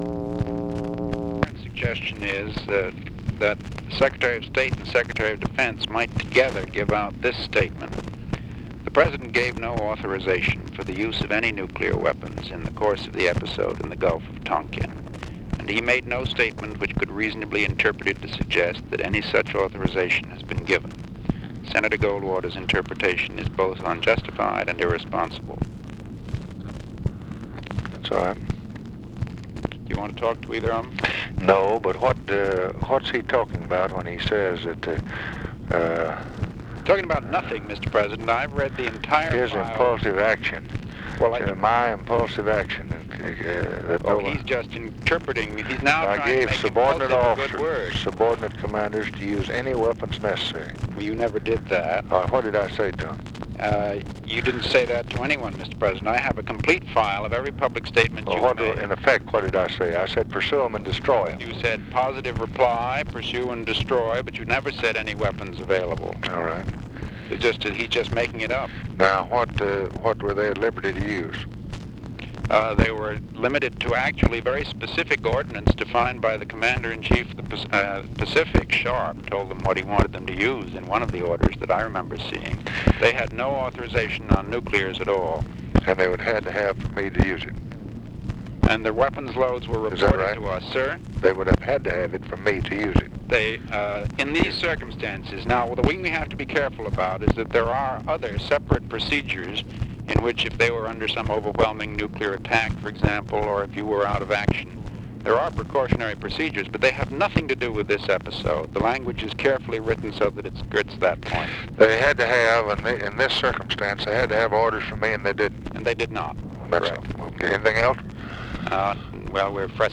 Conversation with MCGEORGE BUNDY, August 12, 1964
Secret White House Tapes